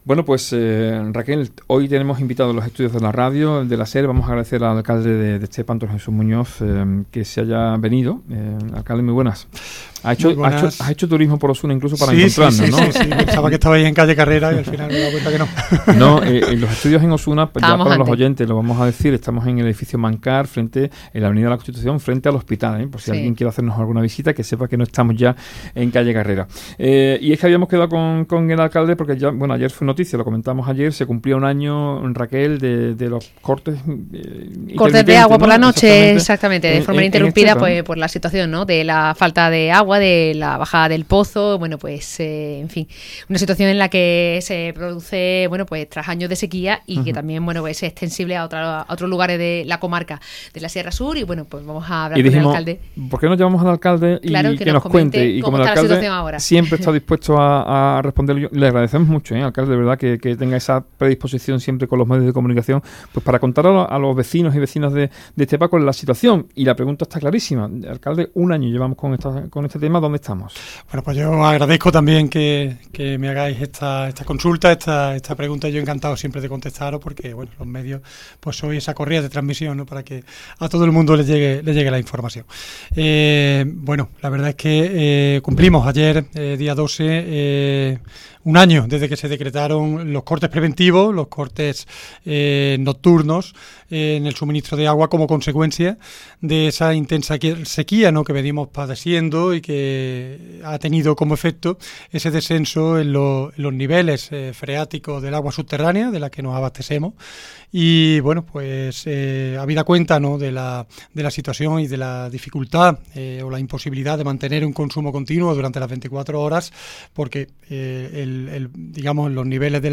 Entrevista | Antonio Jesús Muñoz Quirós, alcalde de Estepa